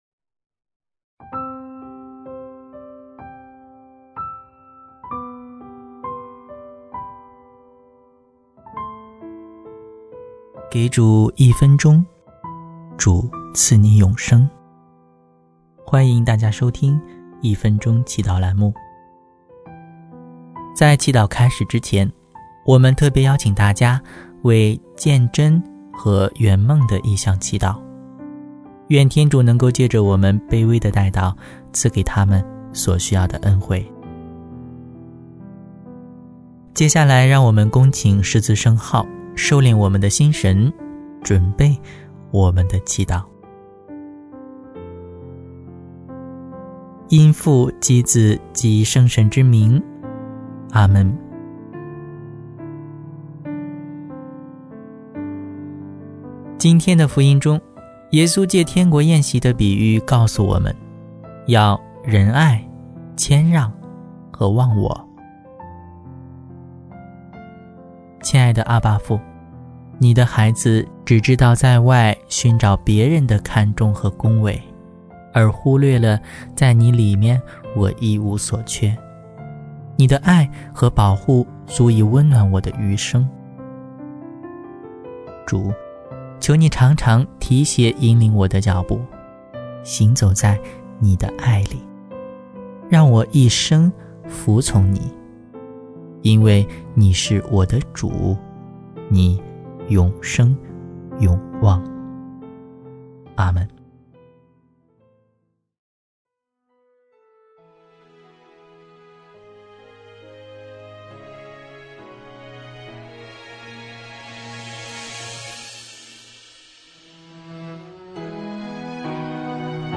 【一分钟祈祷】|主，求祢常常提携引领我的脚步行走在你的爱里（10月30日）